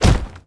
DefendHit.wav